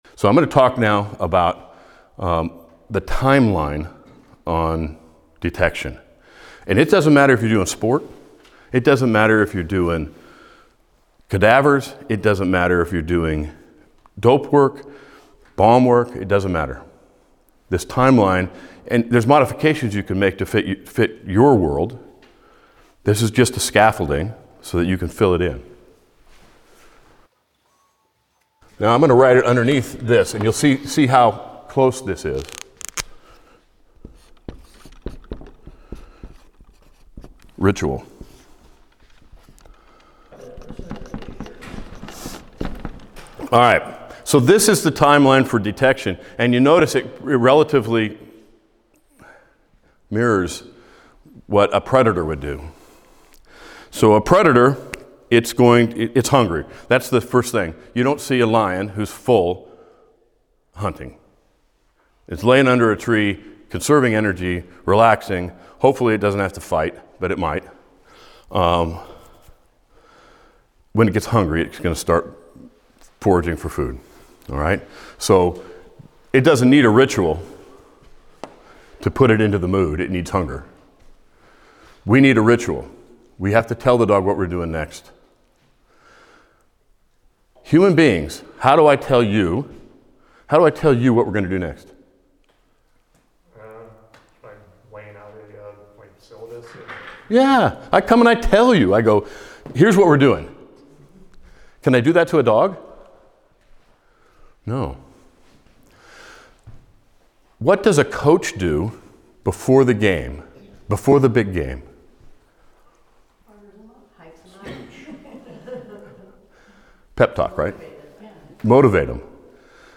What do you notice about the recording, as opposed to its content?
Led by one of the nation’s most qualified detection dog trainers, this three-day workshop covers core foundations for all levels and detection disciplines, including odor recognition, indication, alert behavior, and search development.